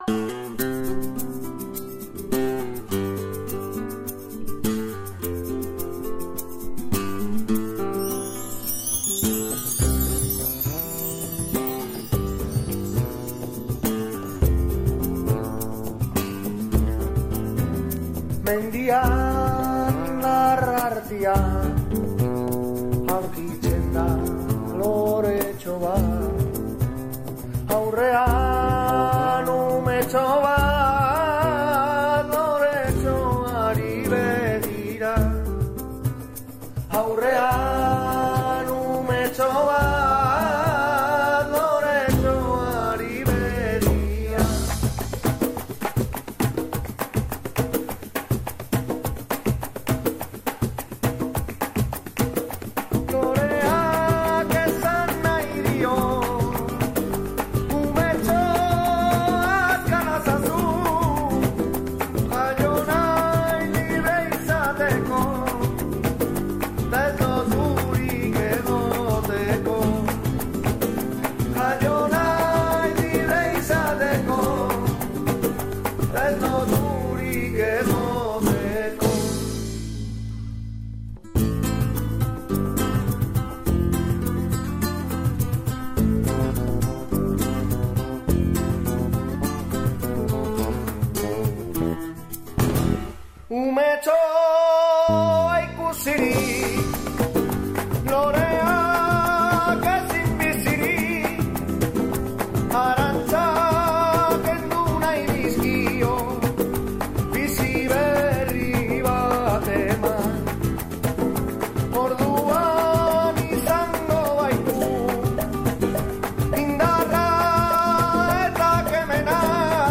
Sonakay, la fusión músical de culturas vasca y gitana